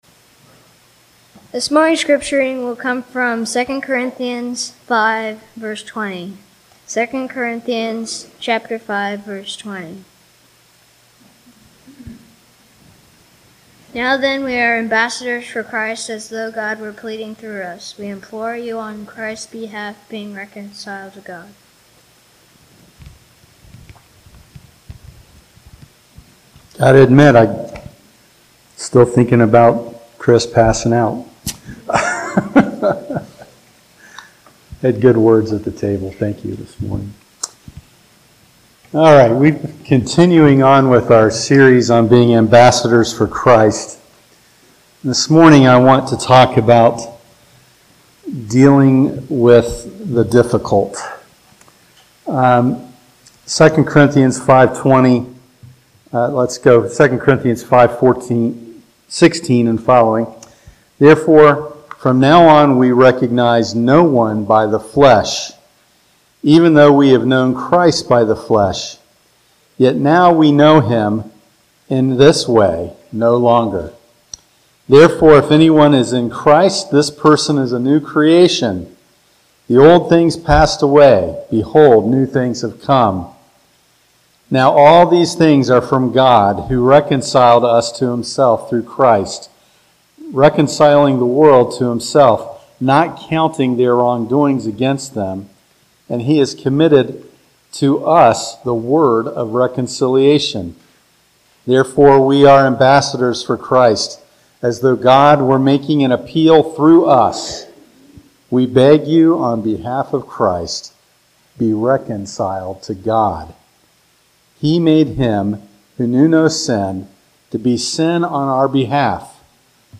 Ambassadors for Christ Passage: 2 Corinthians 5:20 Service: Sunday Morning Topics